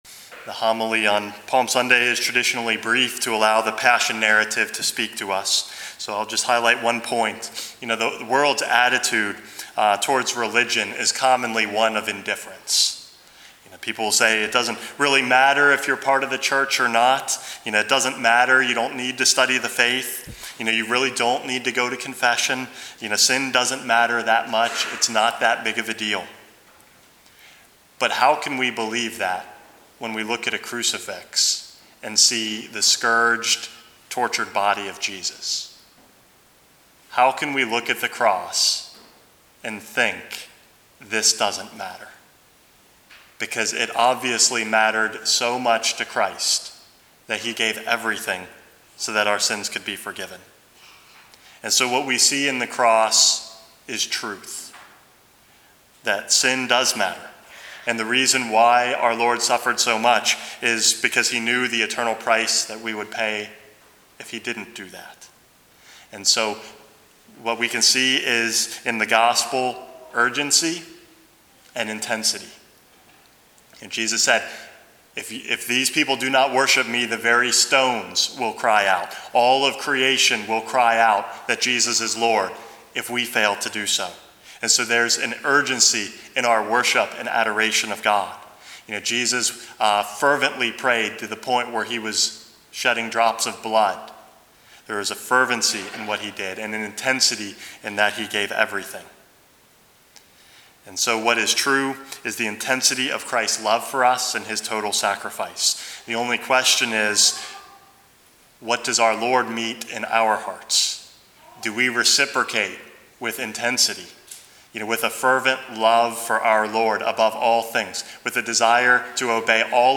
Homily #444 - An Urgent Message